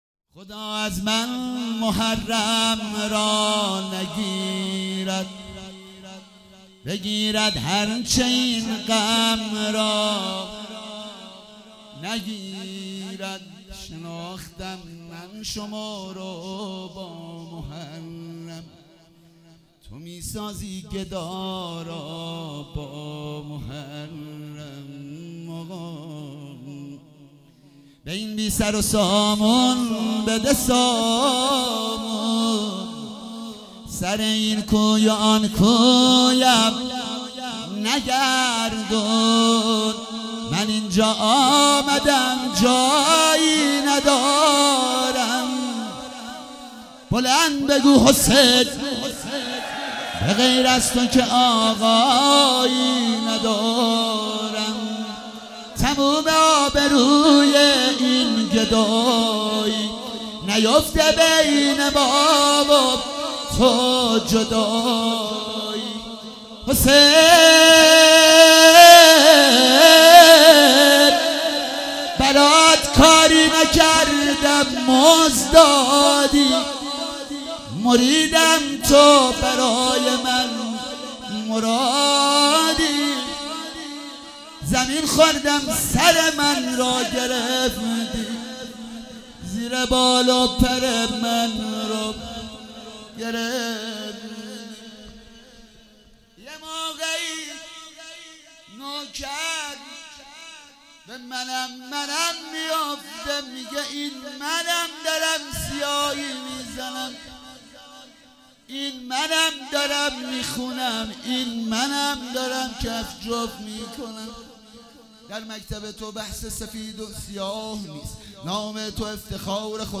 شب پنجم محرم96 - رجز - خدا از من محرم را نگیرد
محرم96
مداحی